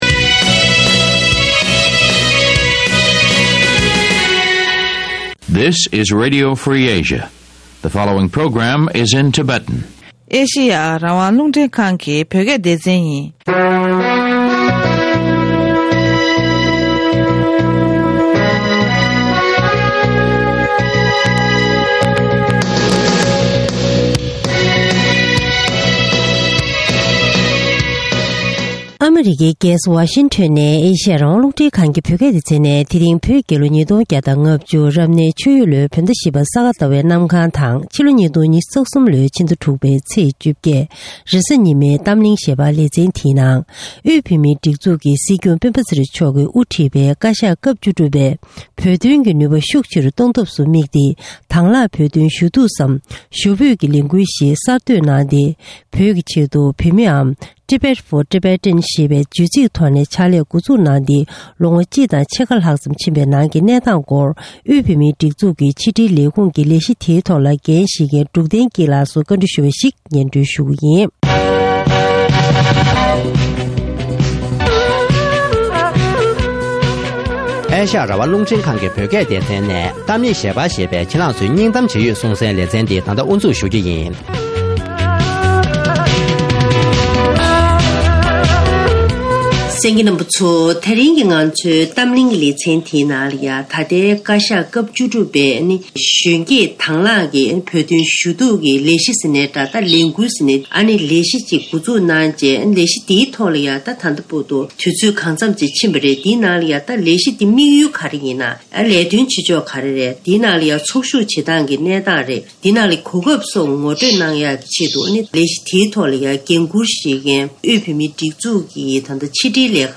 ཐེངས་འདིའི་གཏམ་གླེང་གི་ལེ་ཚན་ནང་།